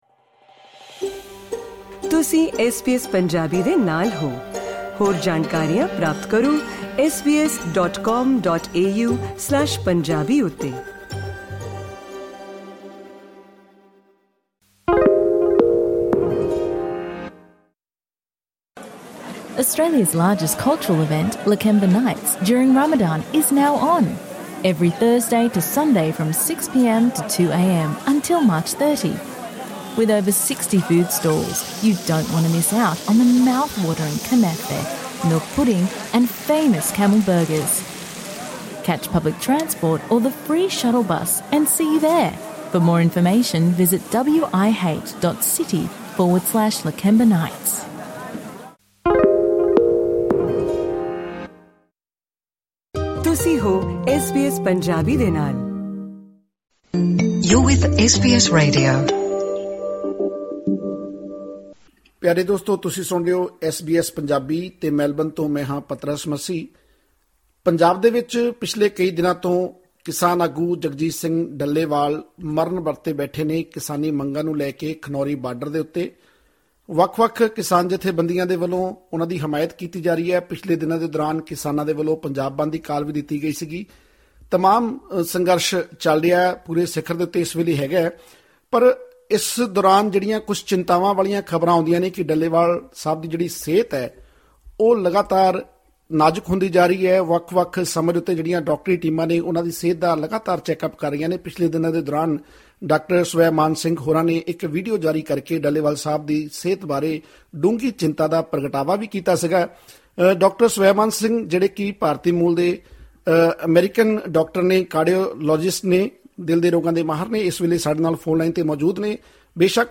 ਹੋਰ ਵੇਰਵੇ ਲਈ ਸੁਣੋ ਇਹ ਗੱਲਬਾਤ…